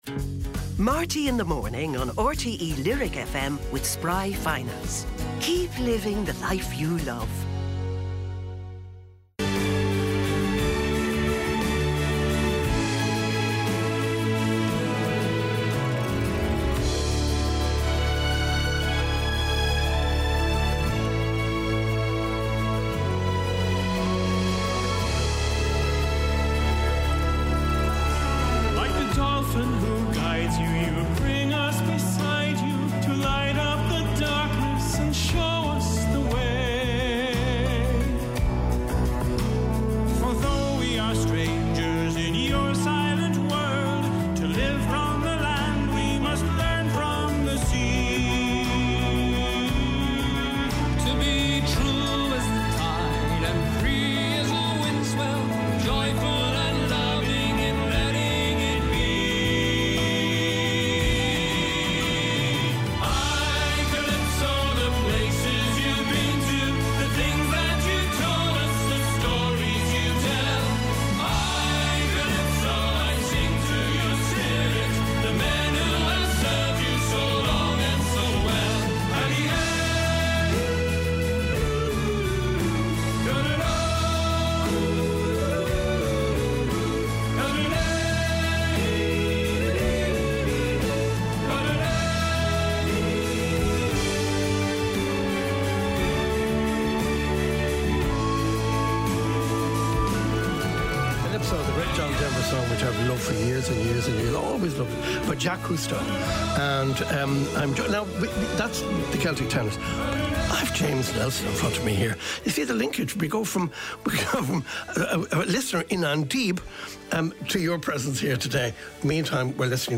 Highlights and special guest interviews and performances from Marty Whelan's breakfast show on RTÉ Lyric FM.